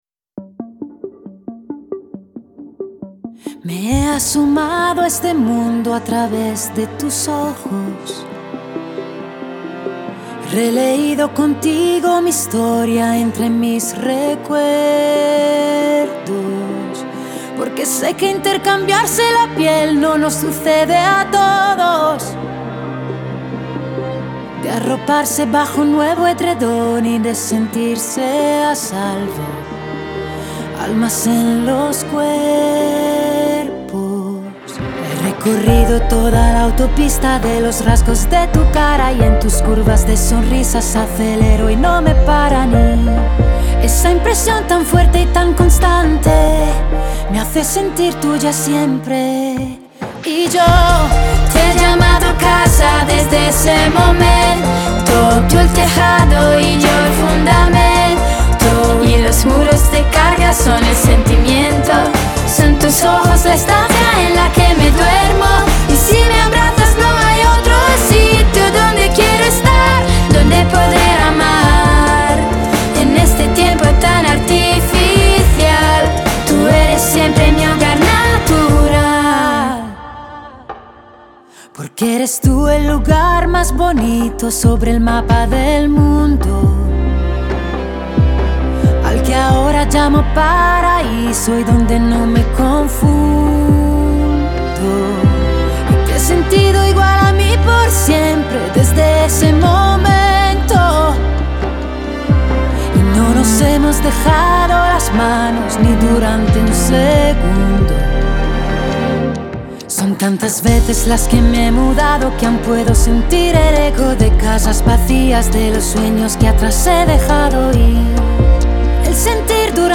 Genre : Latin America